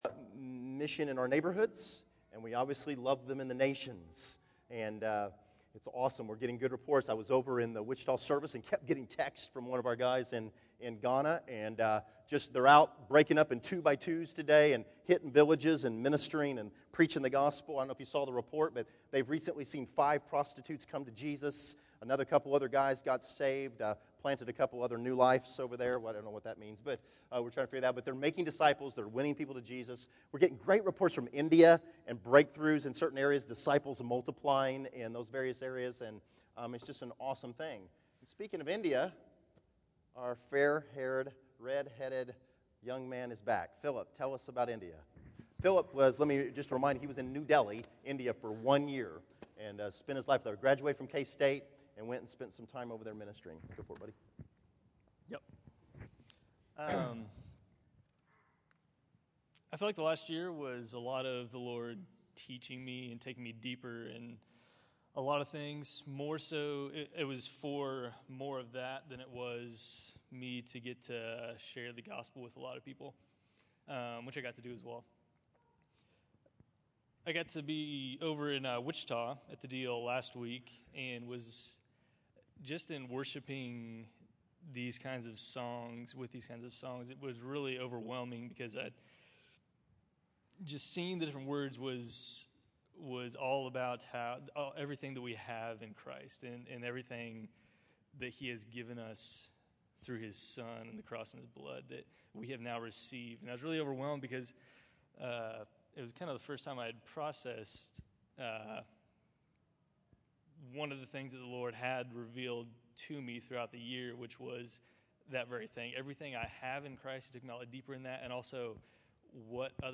July 21, 2013      Category: Testimonies      |      Location: El Dorado